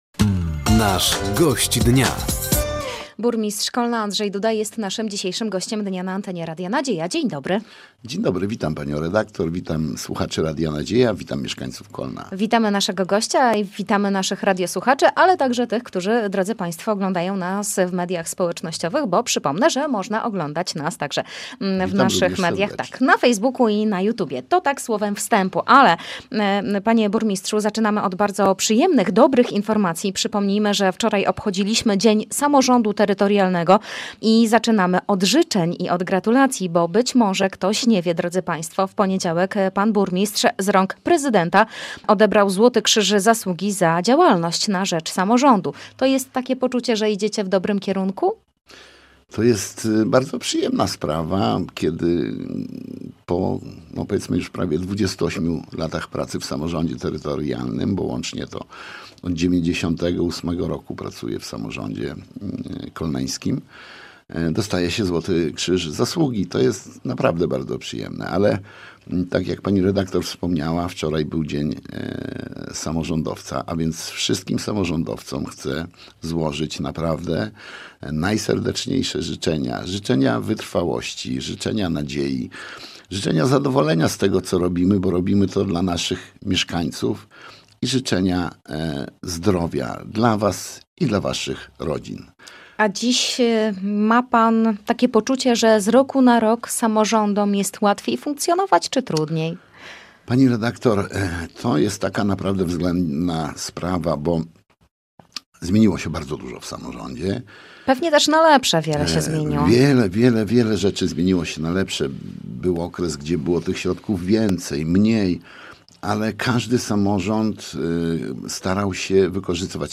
O otrzymanym Złotym Krzyżu Zasługi, kampanii prezydenckiej, a także o zbliżających się wydarzeniach z okazji 600-lecia miasta – mówił na antenie Radia Nadzieja dzisiejszy Gość Dnia, którym był burmistrz Kolna – Andrzej Duda.